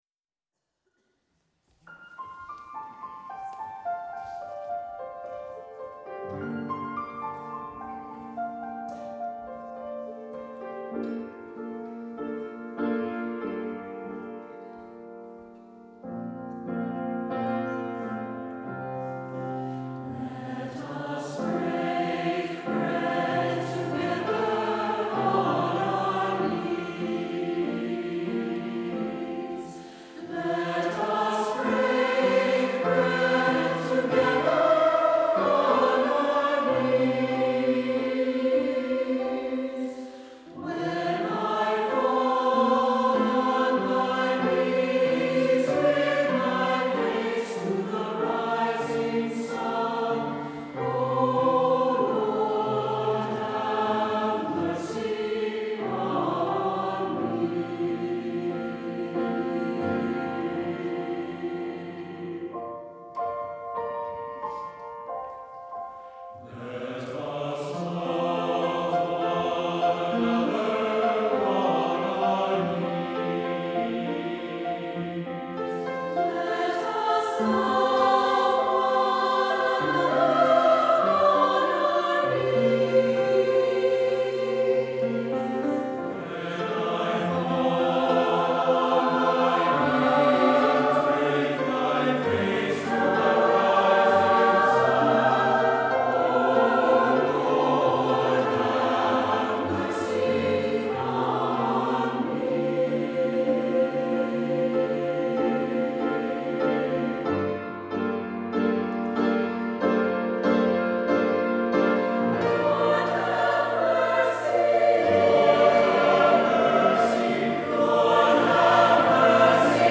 for SATB Chorus and Piano (or String Orchestra) (2017)
SATB and piano